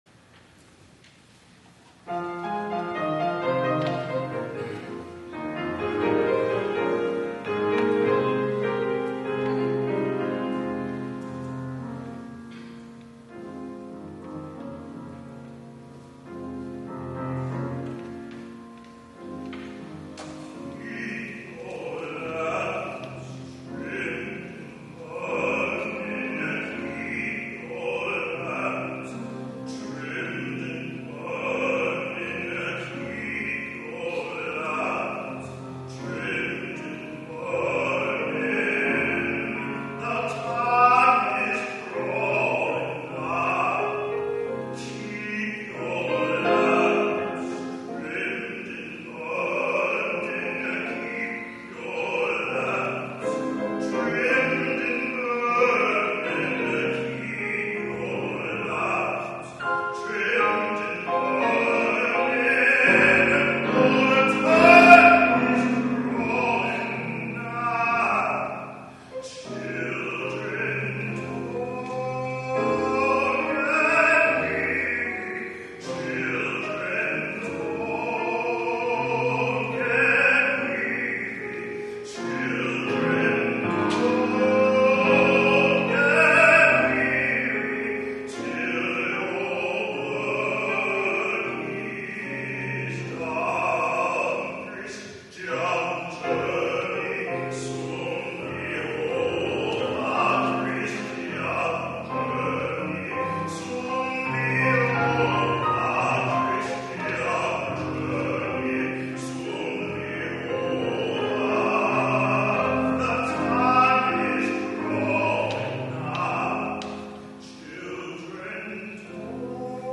2008 media | Morningside Presbyterian Church
guest soloist
pianist